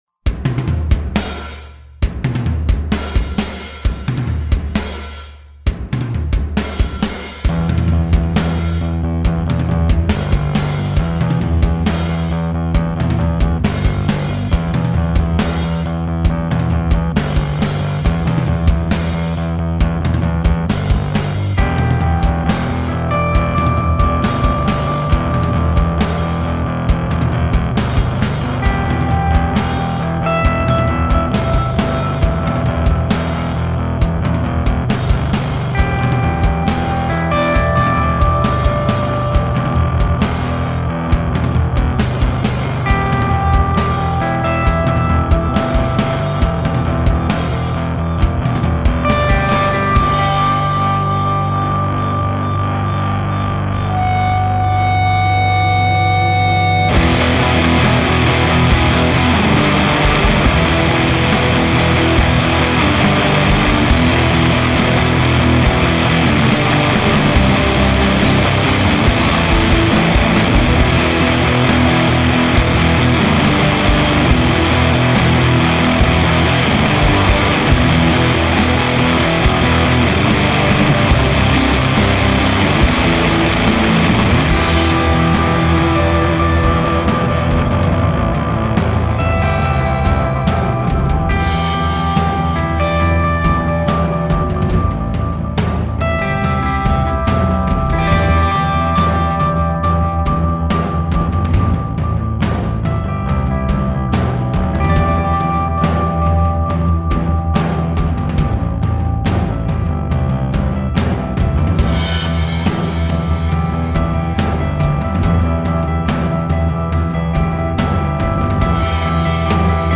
crushing instrumentals
atmsopheric sludge